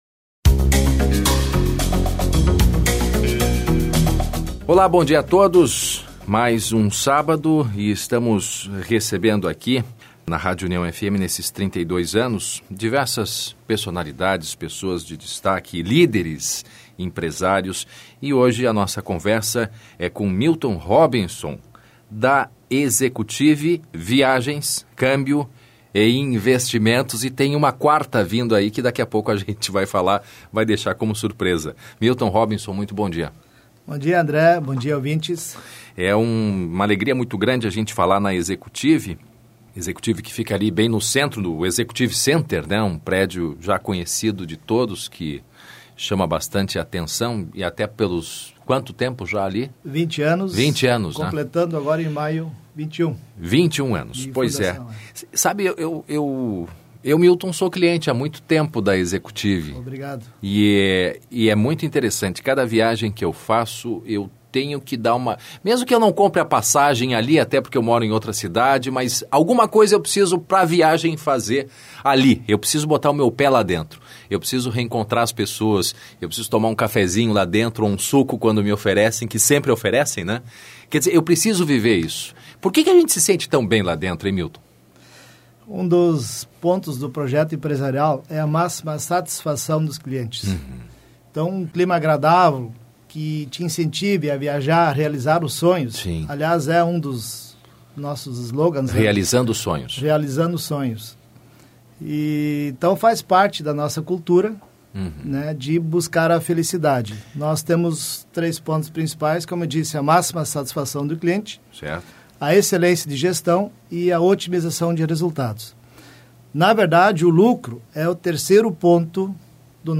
Entrevista para a rádio União